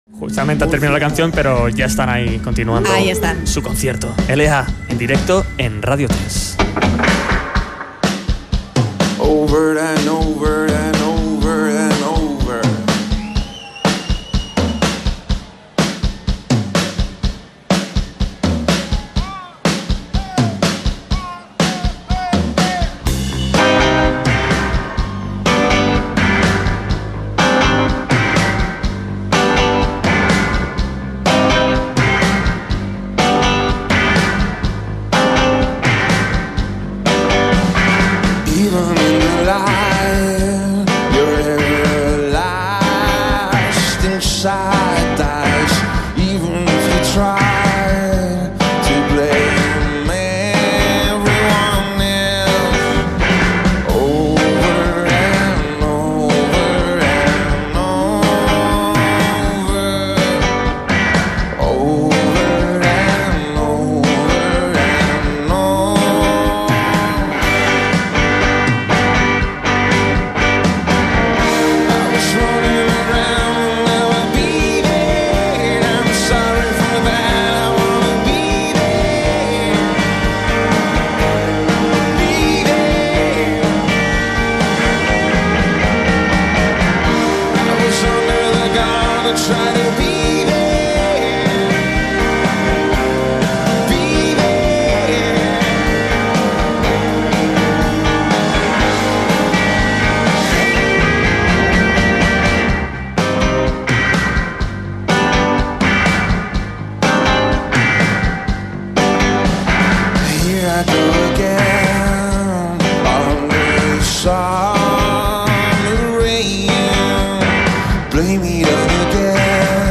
singer/songwriter/guitarist
Indie Goes Latin.